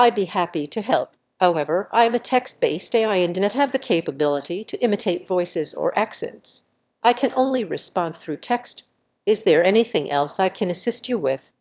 role0_VacuumCleaner_1.wav